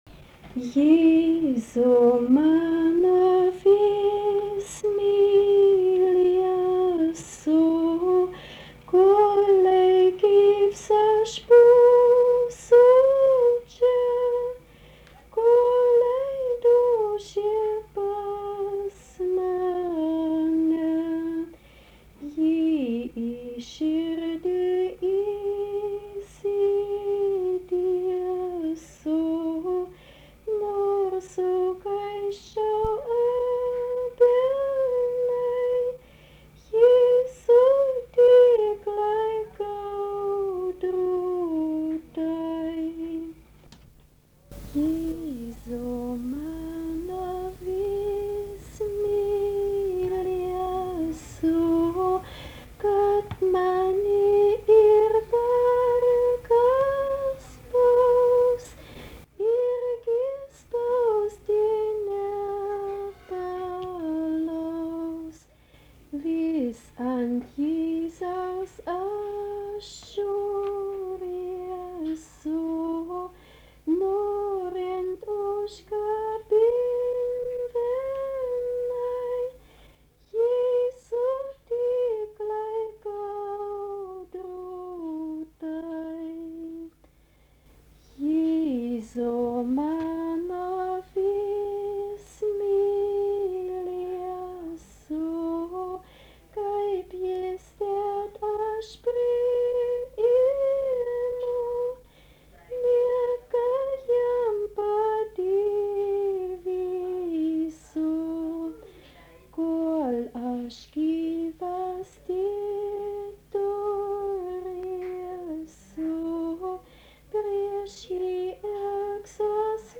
giesmė
Rusnė
vokalinis
Gieda moteris